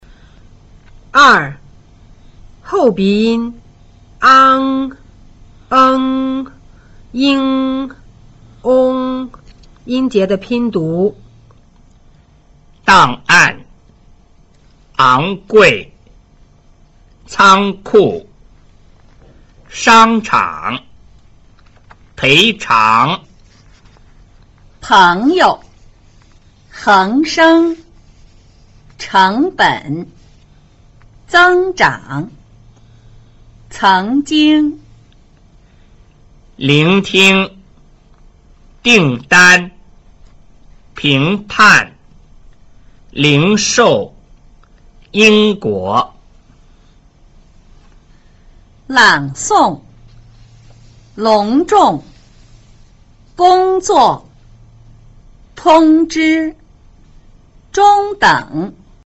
2 後鼻音 ang eng ing ong 音節的拼讀